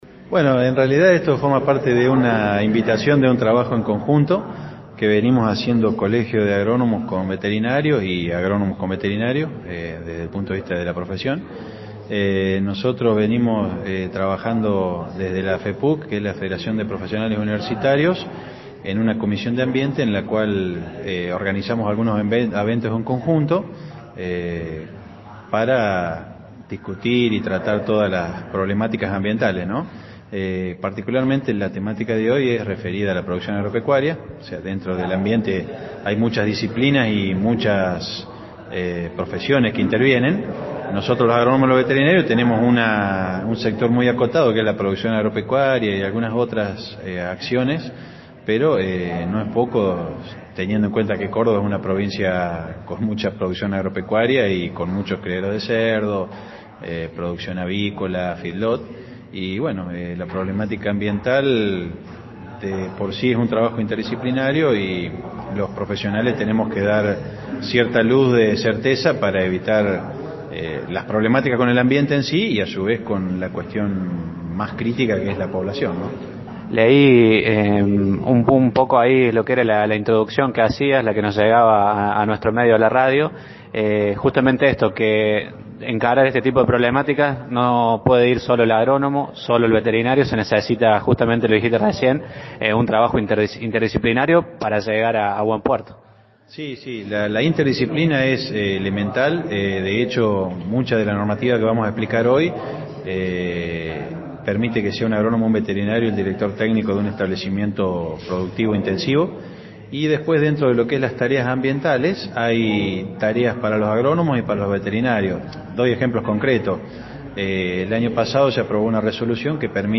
El martes 15 de mayo por la noche y en el Centro Cultural Municipal se desarrollo el Taller de Introducción a la Normativa Ambiental para establecimientos de Producción Animal Intensiva.